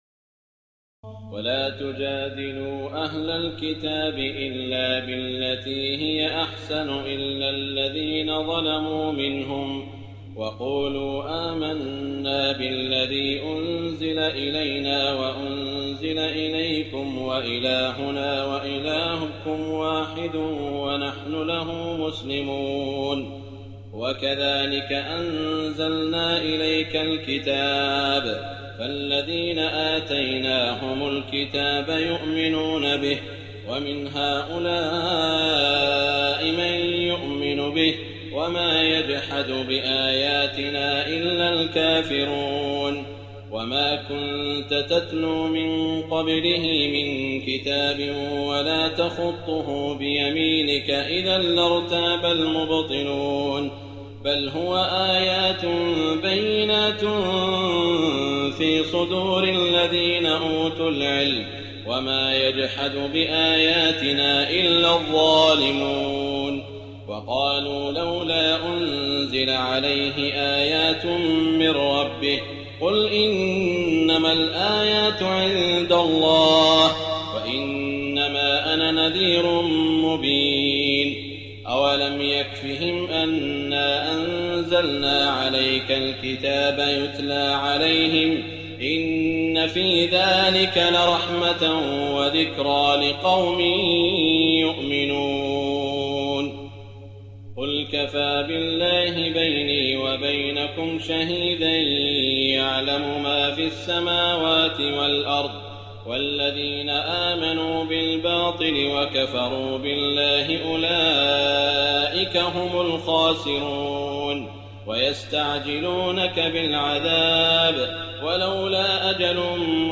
تراويح الليلة العشرون رمضان 1419هـ من سور العنكبوت (46-69) و الروم و لقمان (1-21) Taraweeh 20 st night Ramadan 1419H from Surah Al-Ankaboot and Ar-Room and Luqman > تراويح الحرم المكي عام 1419 🕋 > التراويح - تلاوات الحرمين